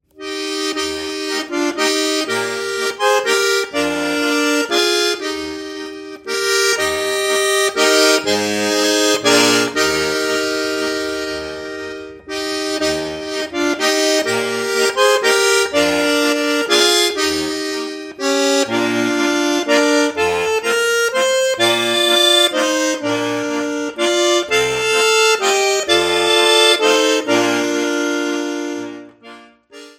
Besetzung: Schwyzerörgeli mit CD